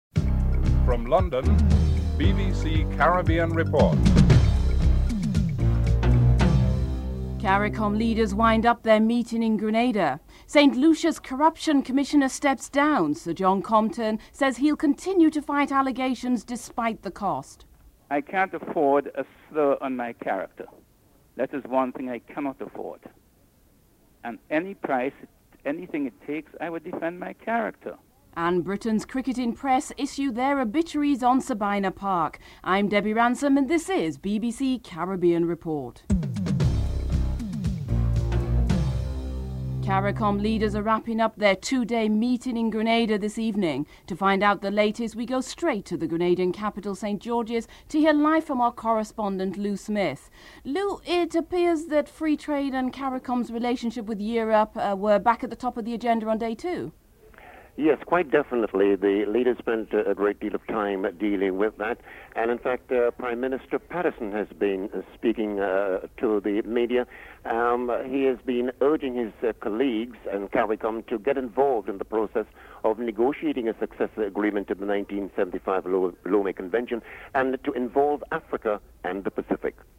2. As CARICOM leaders wrap up a two-day meeting in Grenada, free trade and CARICOM's relationship with Europe were at the top of the agenda. Jamaica's Prime Minister P.J. Patterson speaks about negotiating a new Lome agreement and Antigua's Prime Minister Lester Bird talks about tourism in the region (00:39-04:52)